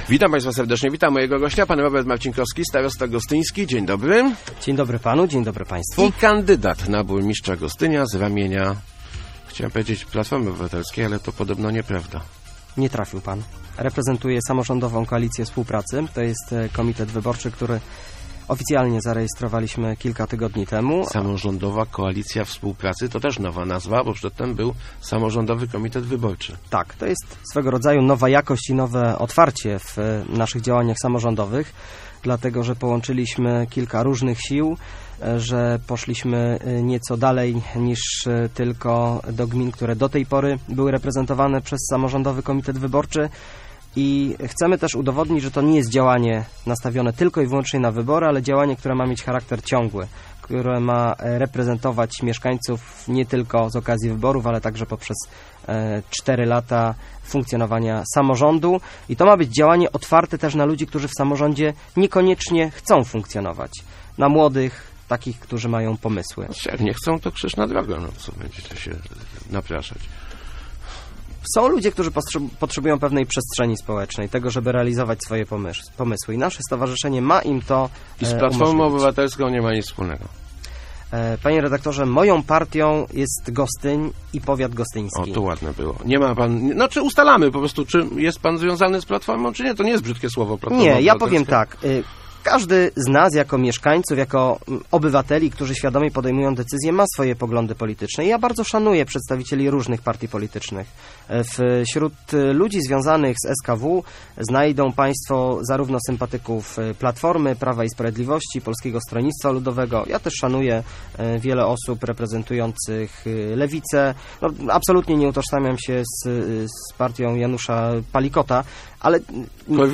Przez ostatnie cztery lata inwestycje w Gostyniu skupiały się na celach wizerunkowych, a nie rozwojowych - mówił w Rozmowach ELki starosta gostyński Robert Marcinkowski, który kandyduje na burmistrza tego miasta.